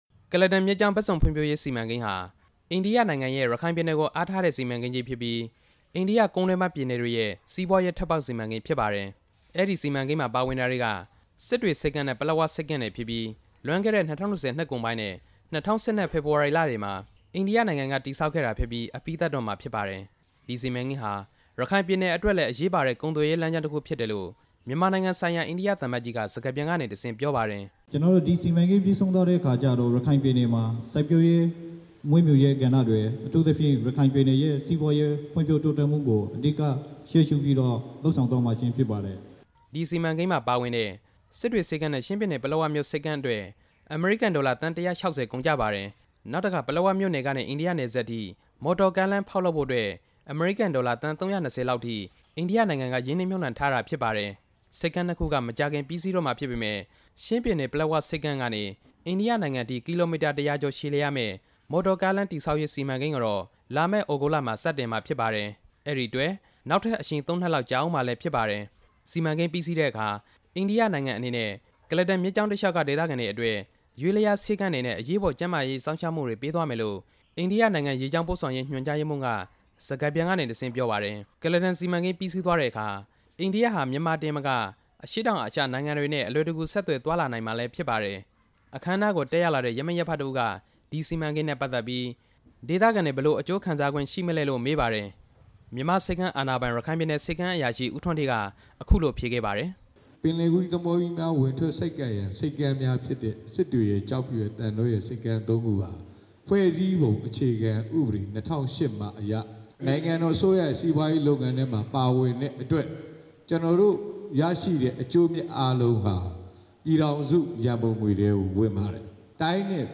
အဲ့ဒီစီမံကိန်းအကြောင်းကို မြန်မာနိုင်ငံဆိုင်ရာ အိန္ဒိယသံအမတ်ကြီး Gautam Mukhopadhaya က စစ်တွေမြို့ ဦးဥတ္တမခန်းမမှာ ဒေသခံတွေကို ဒီနေ့ရှင်းလင်းပြောကြားခဲ့ပါတယ်။